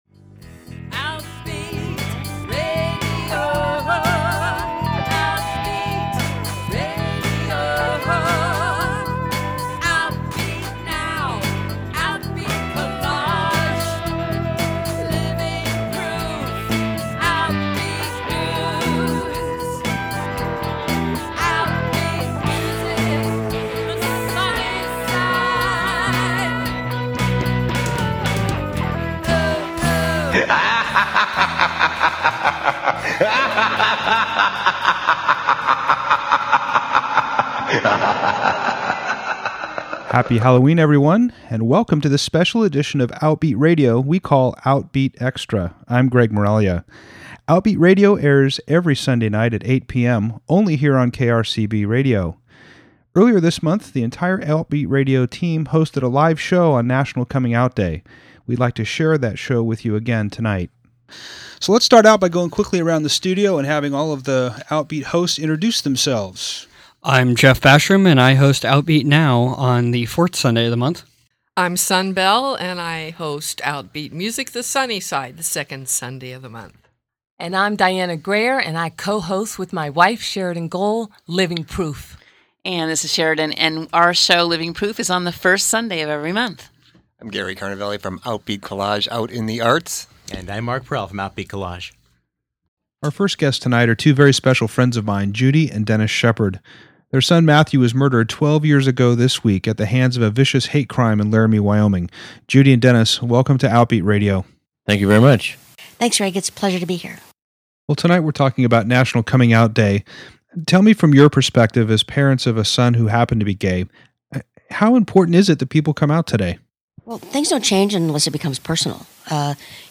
In case you missed the live show on October 11th, we replayed the show for this month’s Outbeat Extra.